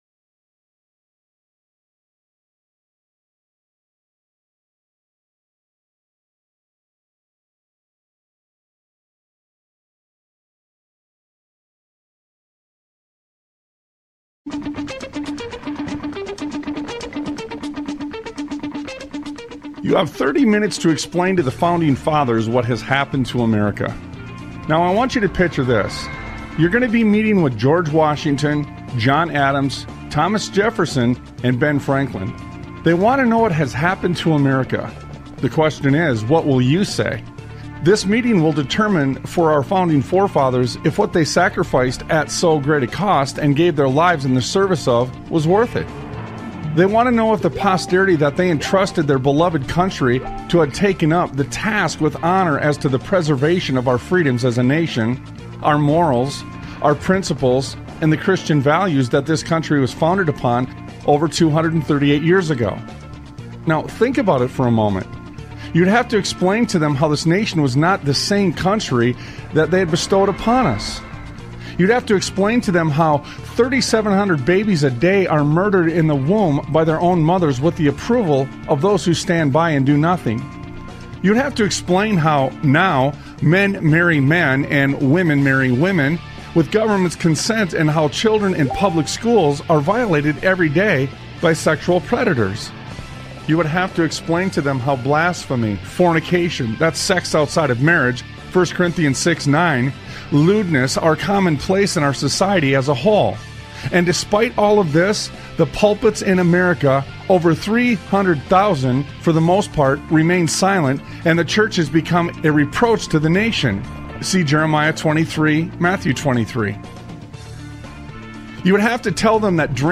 Sons of Liberty Radio Talk Show